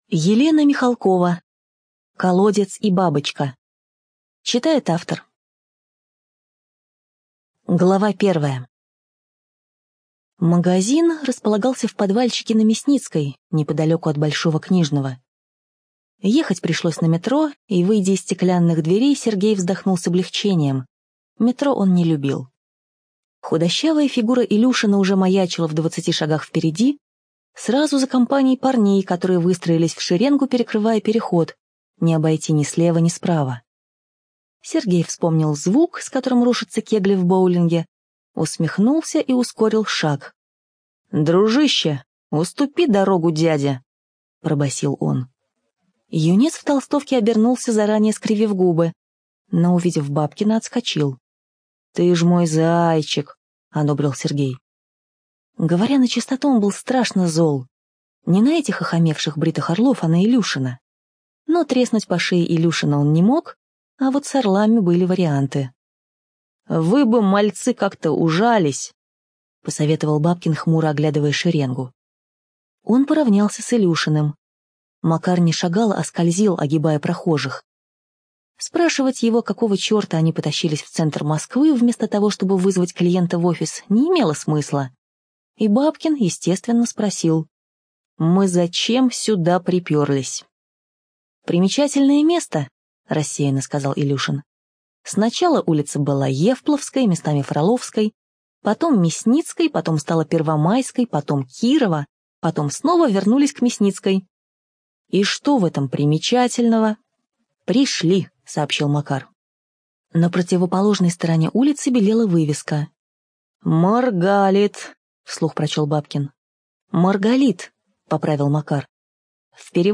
ЧитаетАвтор
ЖанрДетективы и триллеры